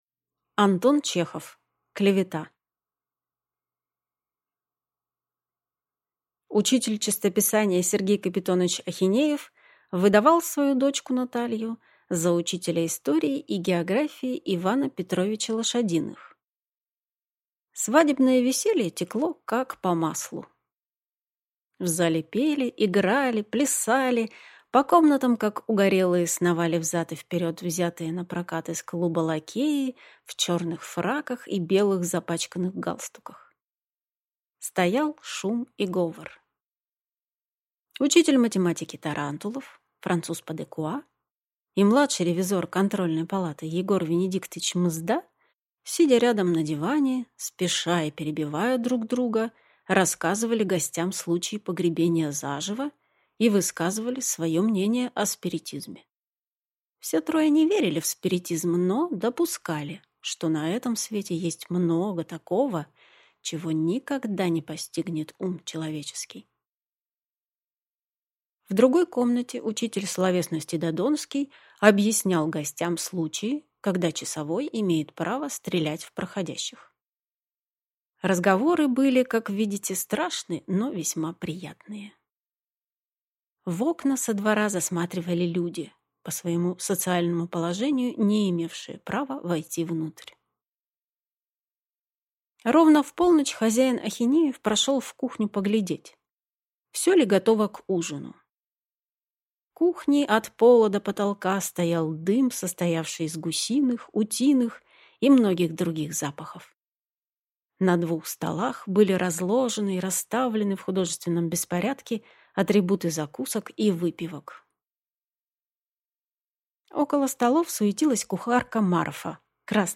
Аудиокнига Клевета | Библиотека аудиокниг